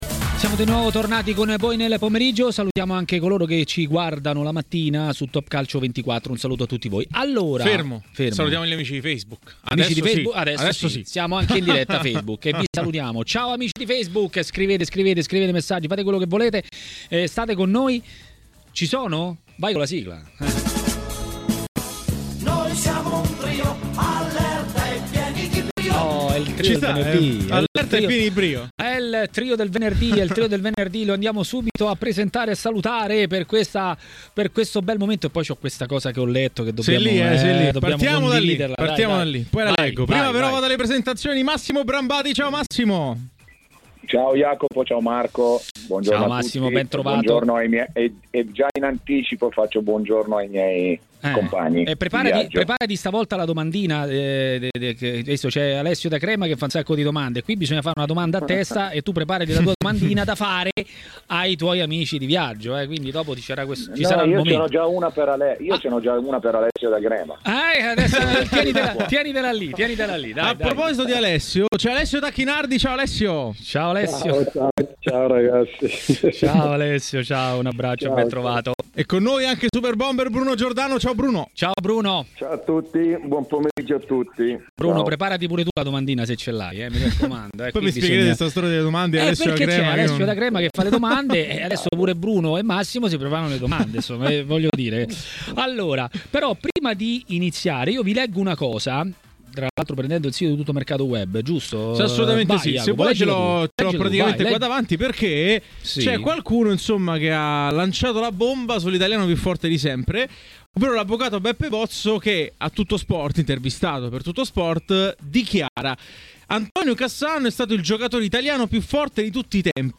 L'ex calciatore e tecnico Bruno Giordano a TMW Radio, durante Maracanà, ha parlato del prossimo turno di campionato e non solo.